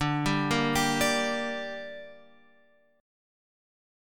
D Suspended 4th Sharp 5th